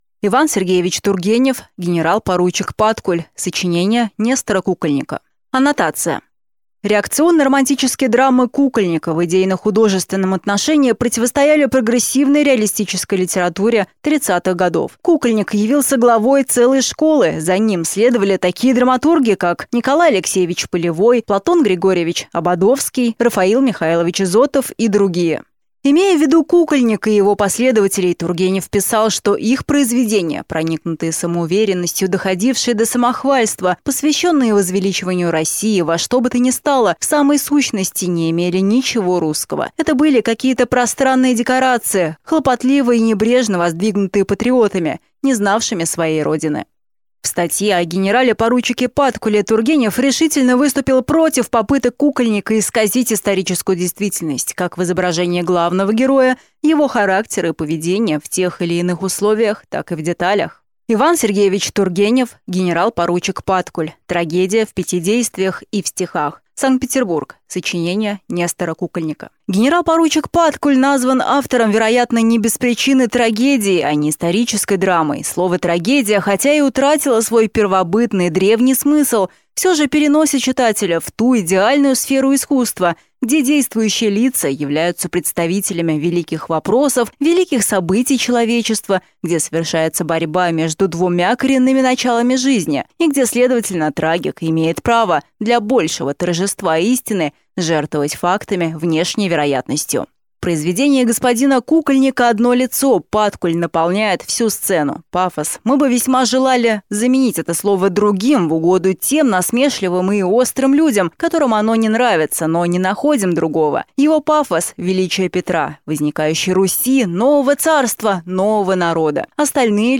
Аудиокнига Генерал-поручик Паткуль. Соч. Нестора Кукольника | Библиотека аудиокниг